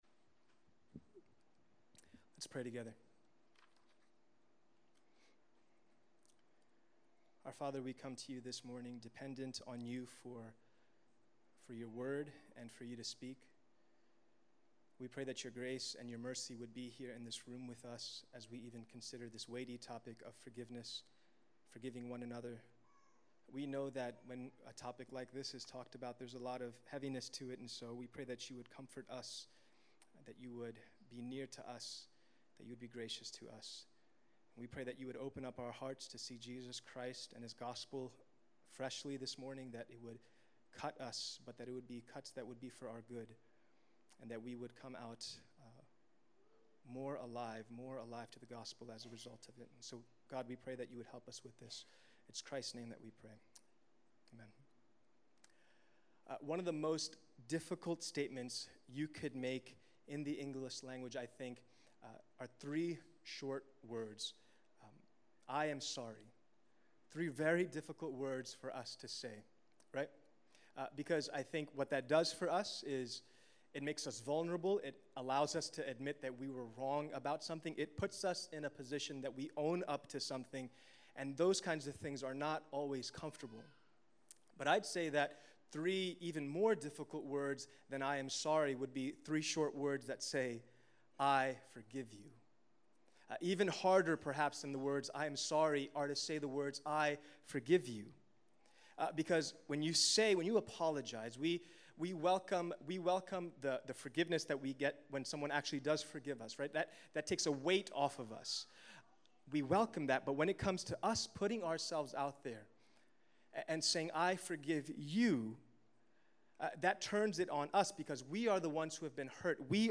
Sermon Reflection- Have You Ever Tasted God's forgiveness? | Seven Mile Road